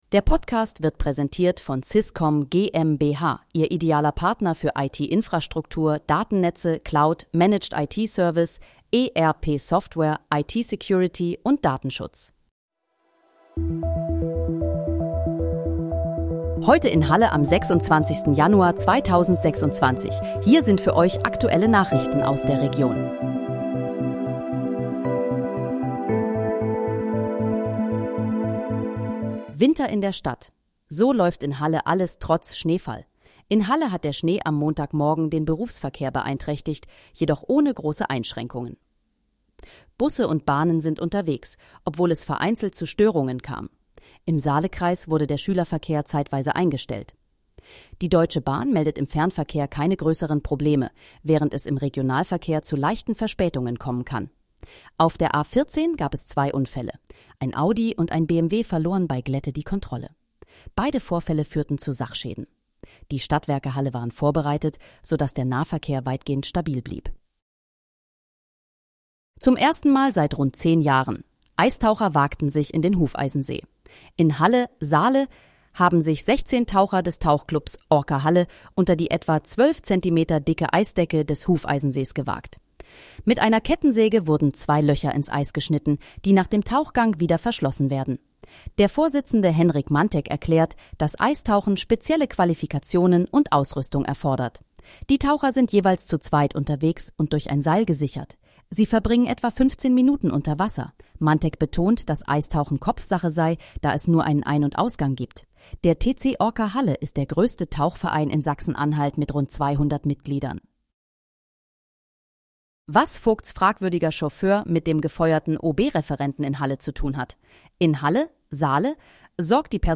Heute in, Halle: Aktuelle Nachrichten vom 26.01.2026, erstellt mit KI-Unterstützung
Nachrichten